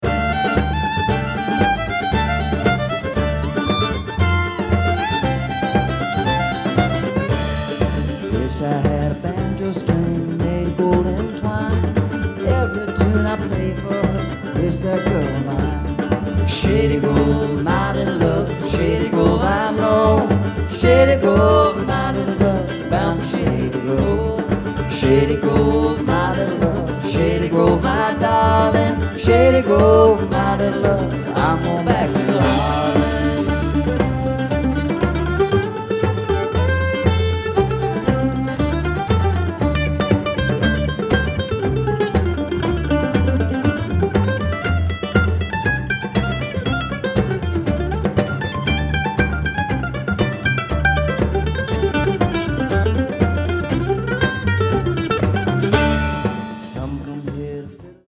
Mystic Theatre • Petaluma, CA